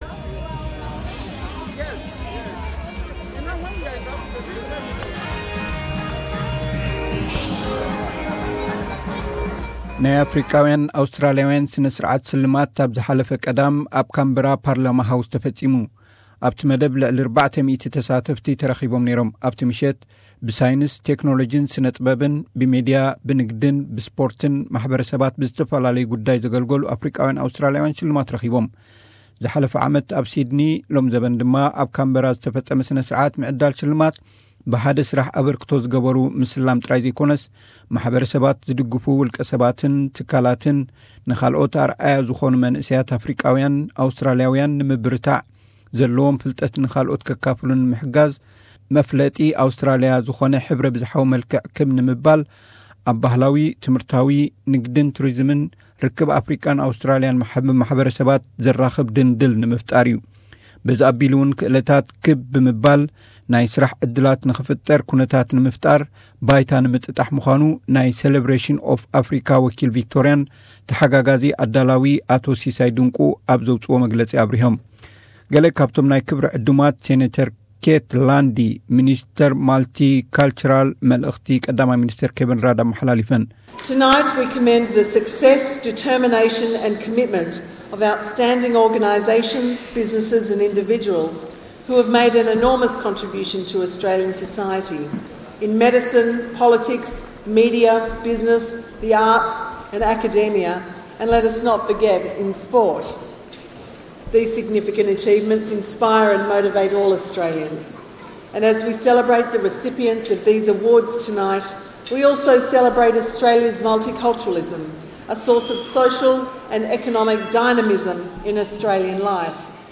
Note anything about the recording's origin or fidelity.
The annual celebrations of African-Australians national awards was held in Canberra listen to the report of the event.